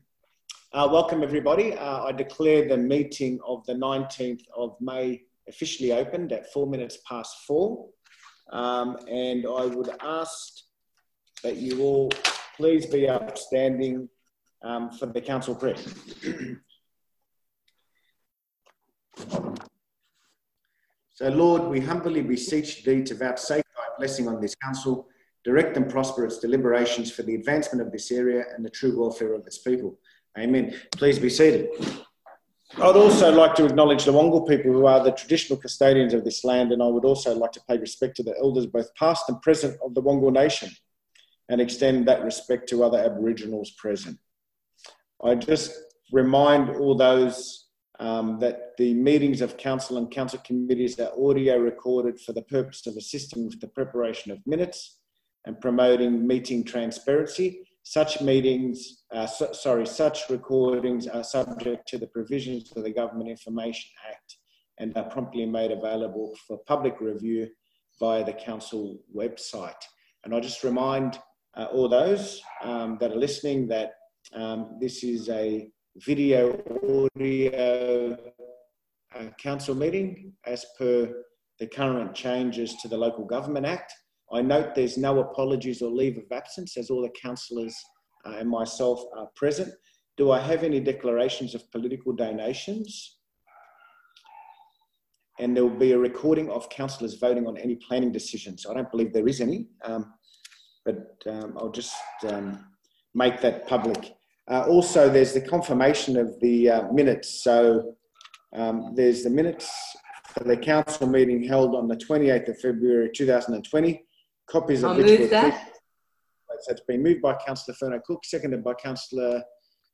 Notice is hereby given that a meeting of the Council of Burwood will be held electronically on Tuesday, 19 May 2020 at 4:00pm to consider the matters contained in the attached Agenda.
may-council-meeting-volume-adjusted.mp3